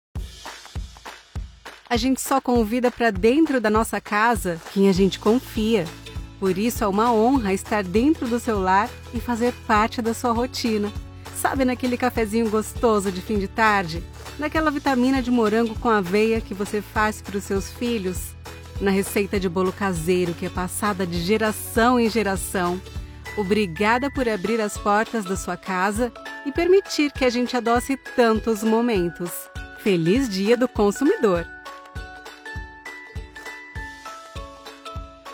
Émissions de télévision
Her voice is pleasant and versatile and can help you create a unique and captivating listening experience for your audience.
I have a professional home studio with all the bells and whistles.